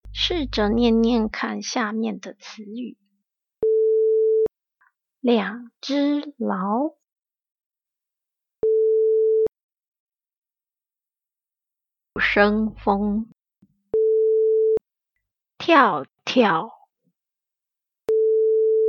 試著唸唸看下面的詞語：聽到嘟聲後開始。